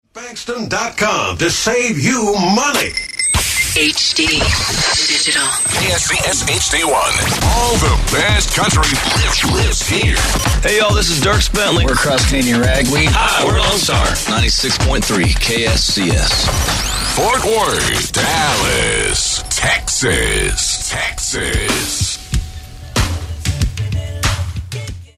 KSCS Top of the Hour Audio: